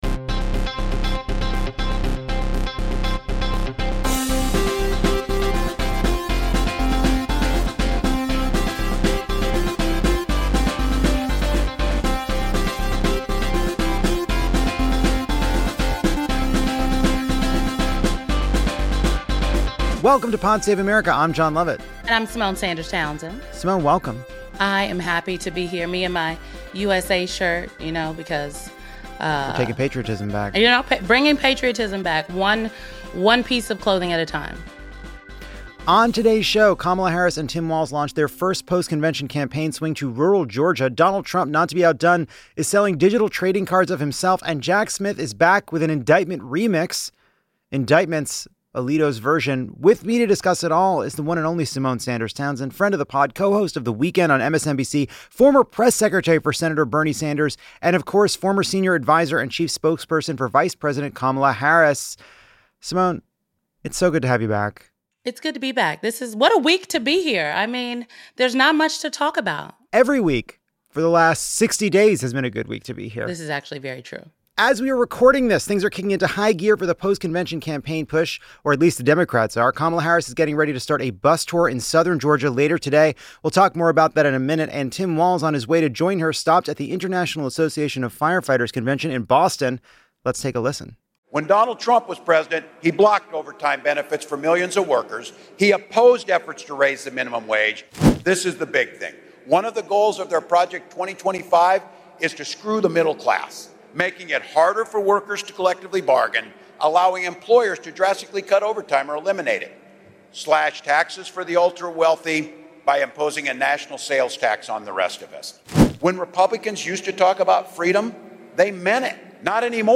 Lovett and guest host Symone Sanders Townsend of MSNBC discuss the Harris-Walz campaign's latest moves: new ads hitting Trump and touting her housing plan, and a big bus tour through southeastern Georgia.